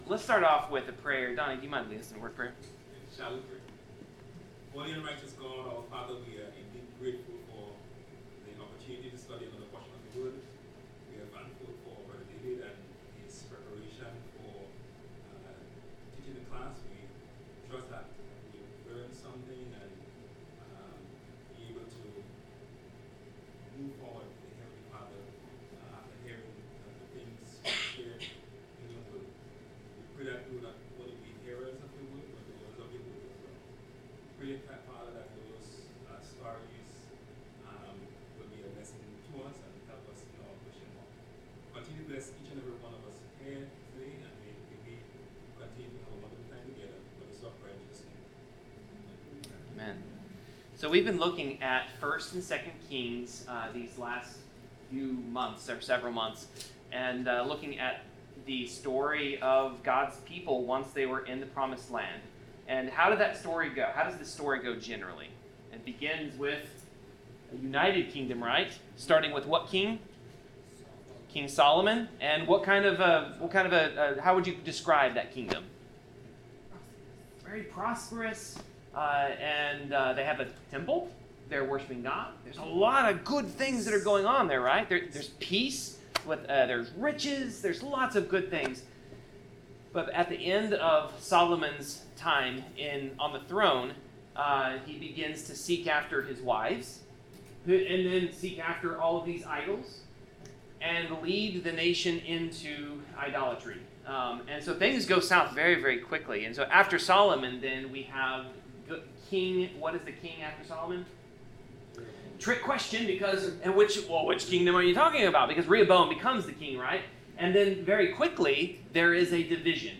Bible class: 2 Kings 8-10
Service Type: Bible Class